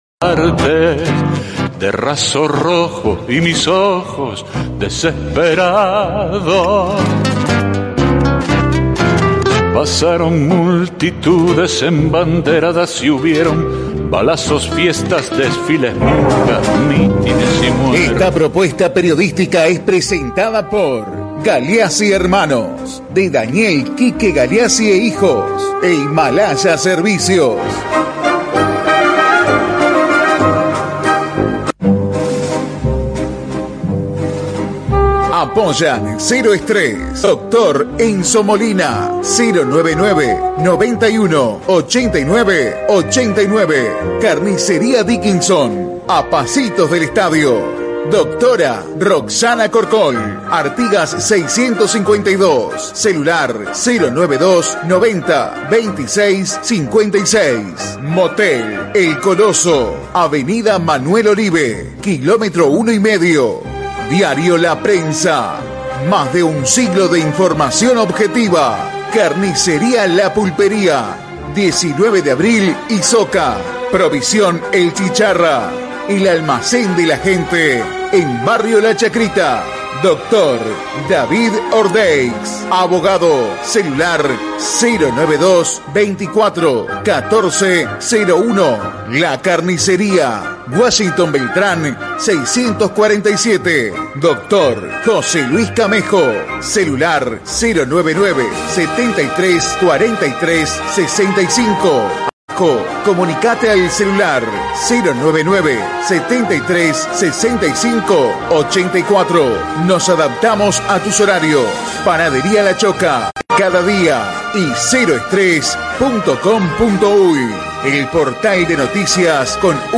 En entrevista con el programa Cero Estrés, emitido por Radio Turística, el coordinador de Cultura, Pablo Bonet, confirmó el inicio de una investigación para conocer qué pasó con los objetos patrimoniales que integraban el Museo Histórico de Salto.